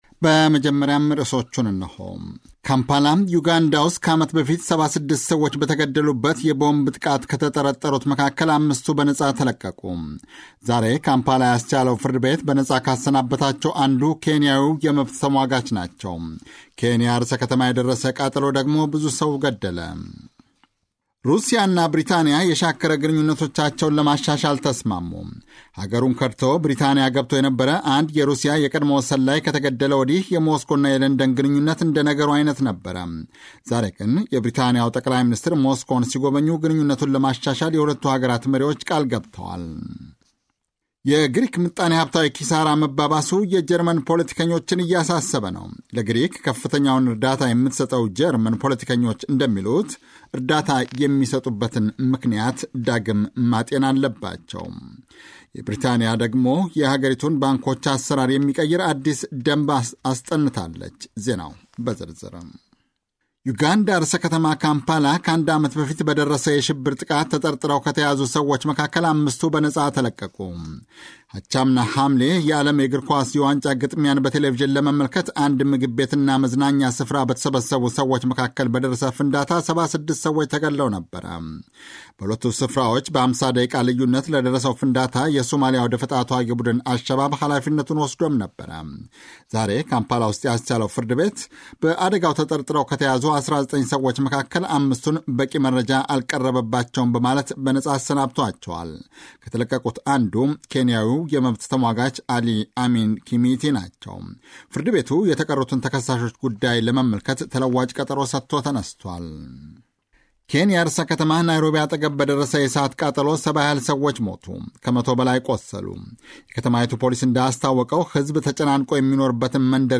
ጀርመን ራዲዮ ዜናዎች – Sep 10, 2011